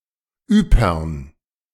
; West Flemish: Yper; German: Ypern [ˈyːpɐn]
De-Ypern.ogg.mp3